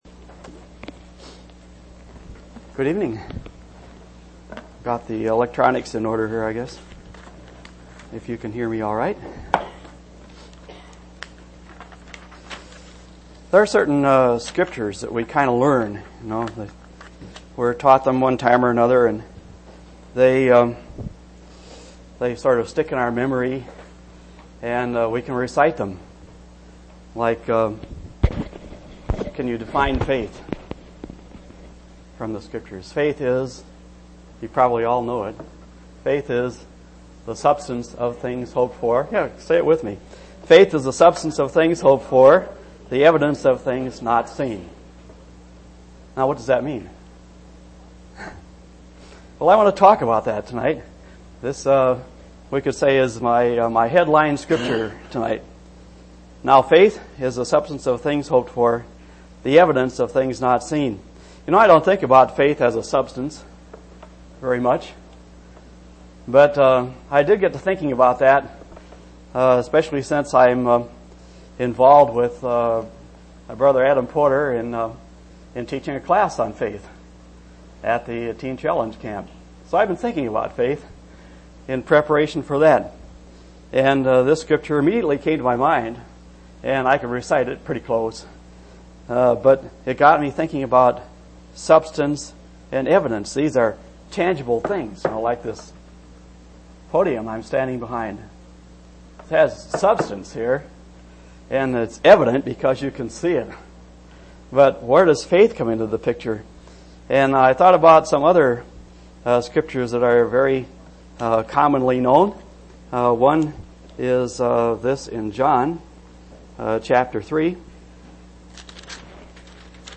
7/19/1998 Location: East Independence Local Event